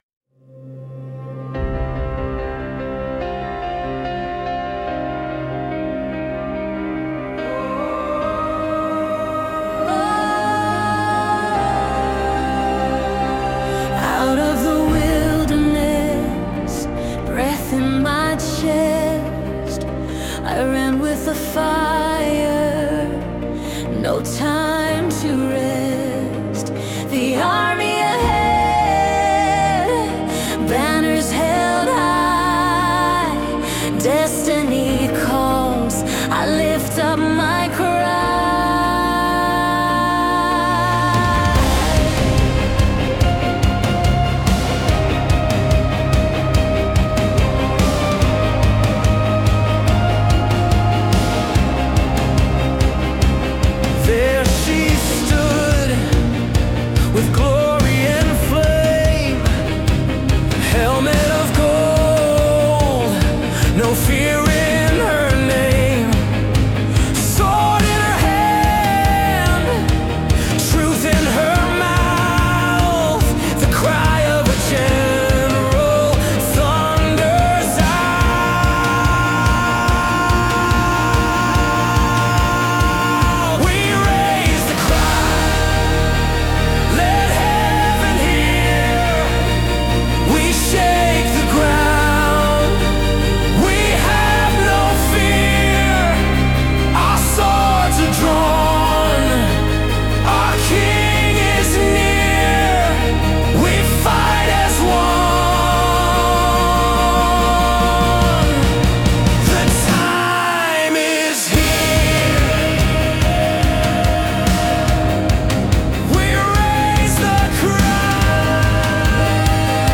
This is a powerful anthem of spiritual war.
• Genre: Christian Rock / Worship Rock